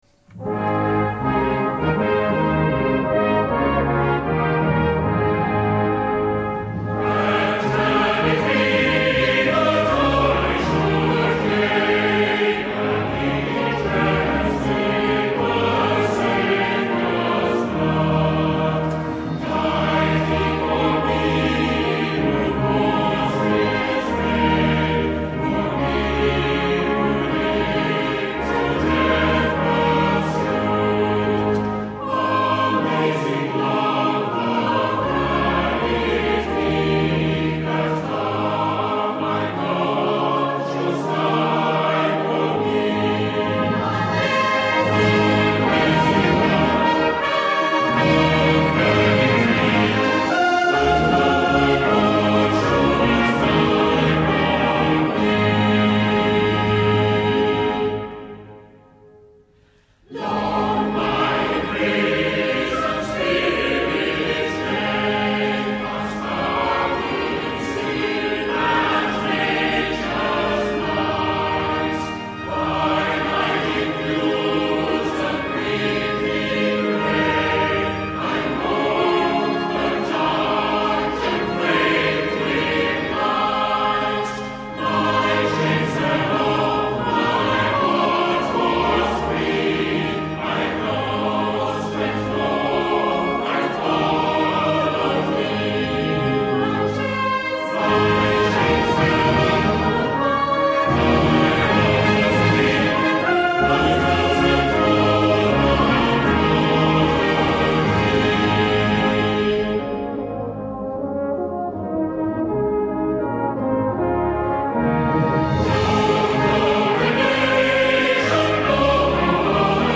Organ Voluntaries for the current month